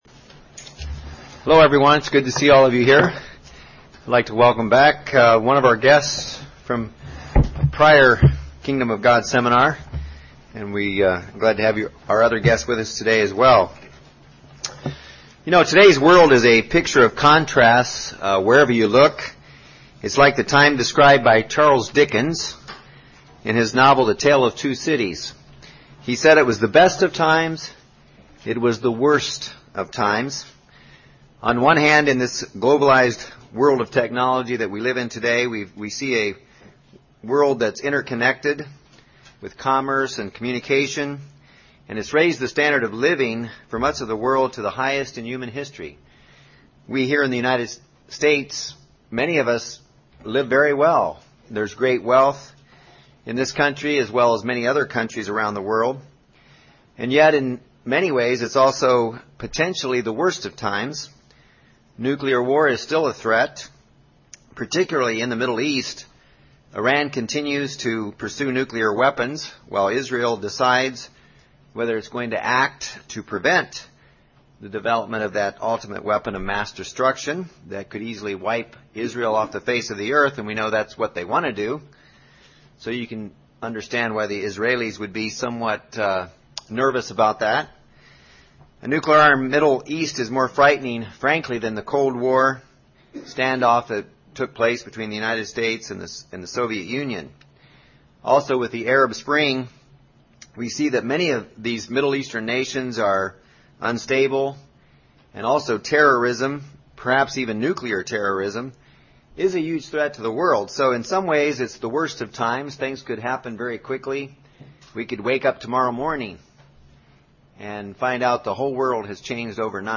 Kingdom of God Bible Seminar, Session 1 The coming Kingdom of God is mankind's only hope for survival.